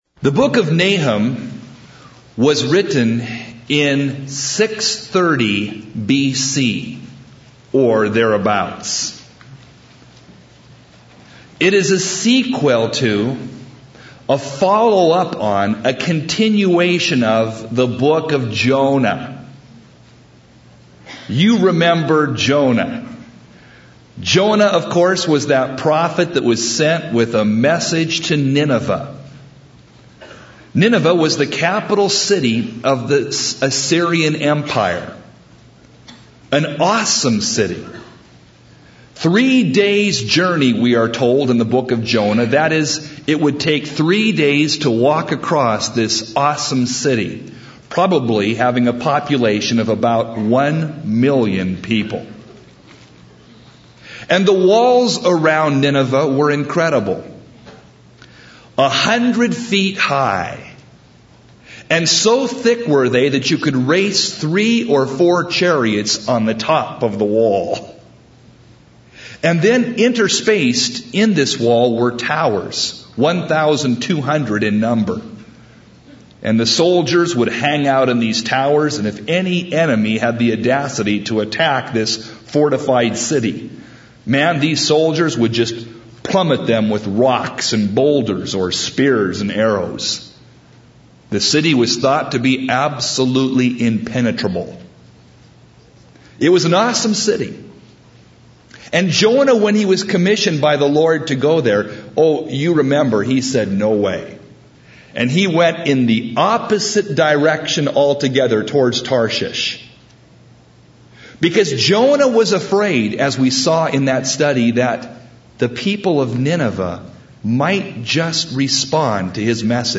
01 Verse by Verse Teaching